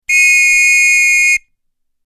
Police-whistle-sound